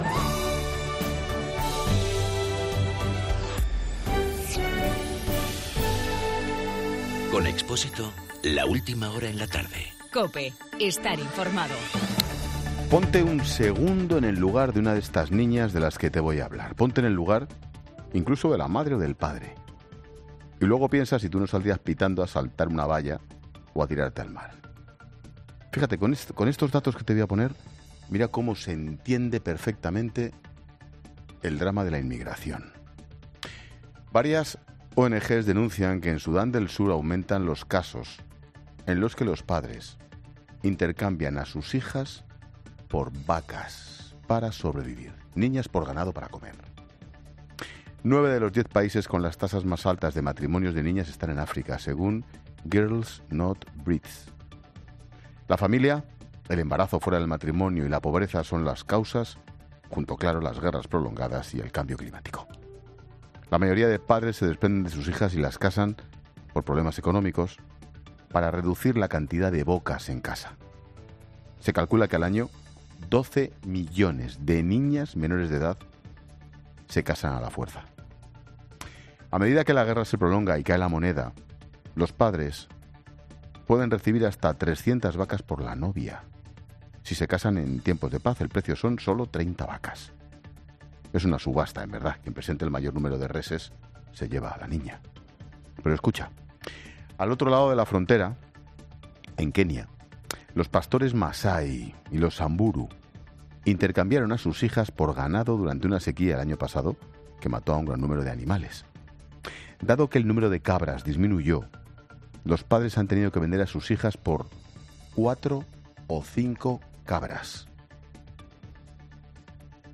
Monólogo de Expósito
El comentario de Ángel Expósito sobre inmigración.